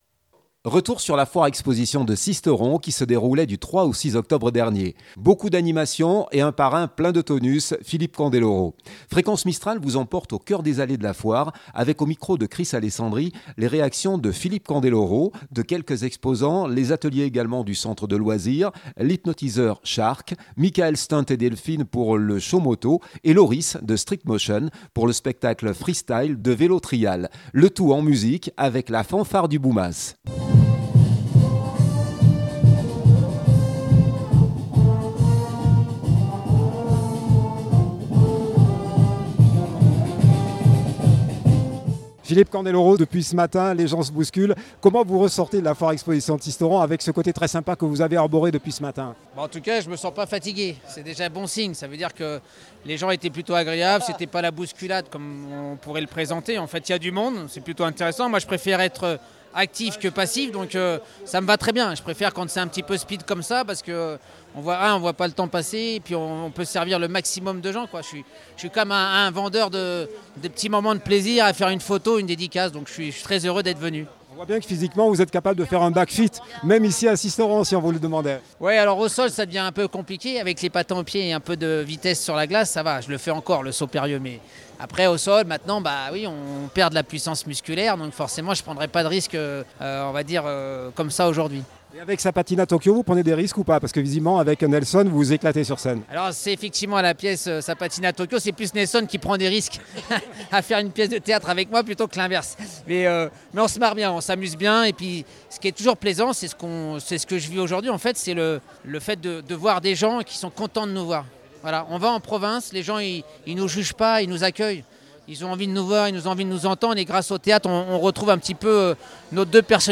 LA FOIRE EXPO DE SISTERON EN LIVE
EMISSION LIVE FOIRE EXPOSITION DE SISTERON.mp3 (36.1 Mo)